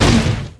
rhinostep_01.wav